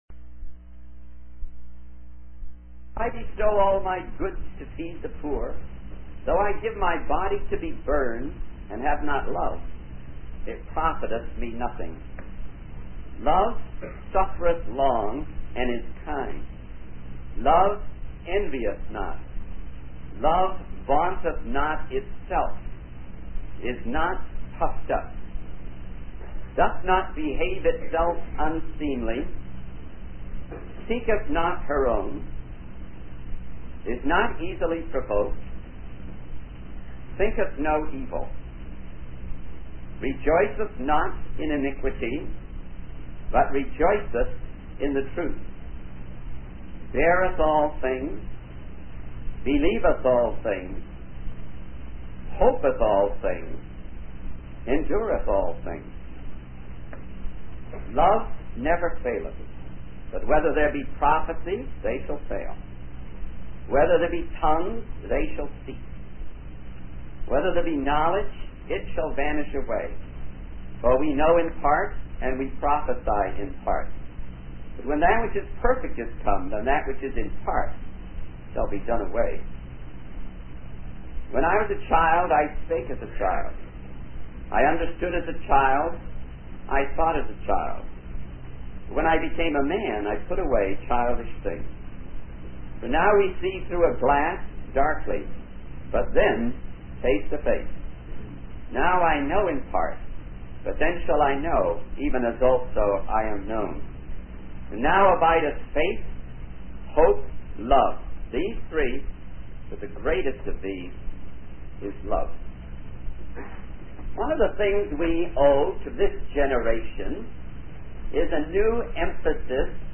In this sermon, the speaker emphasizes the importance of love in action. Love is not just a feeling, but it is seen in the practical things we do for others.